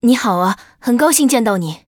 文件 文件历史 文件用途 全域文件用途 Yoshua_tk_03.ogg （Ogg Vorbis声音文件，长度1.9秒，110 kbps，文件大小：25 KB） 文件说明 源地址:游戏语音解包 文件历史 点击某个日期/时间查看对应时刻的文件。 日期/时间 缩略图 大小 用户 备注 当前 2019年1月20日 (日) 04:28 1.9秒 （25 KB） 地下城与勇士  （ 留言 | 贡献 ） 分类:寒冰之休亚 分类:地下城与勇士 源地址:游戏语音解包 您不可以覆盖此文件。